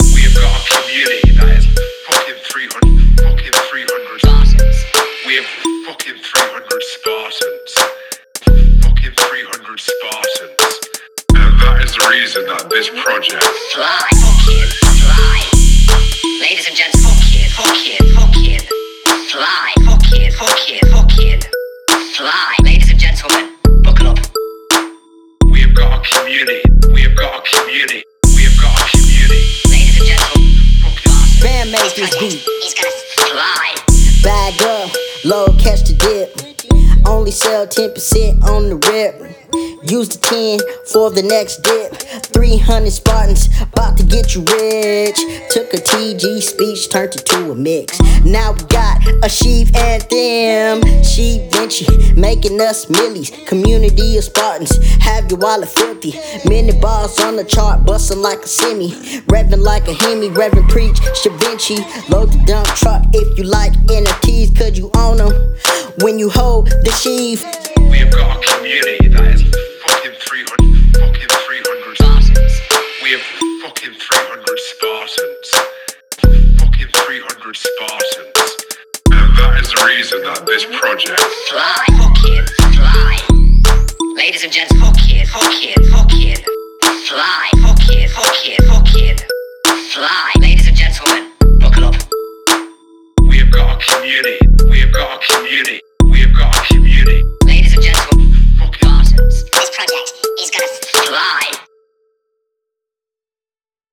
I made this beat in 1 hour, wrote and recorded this song on my phone the following hour.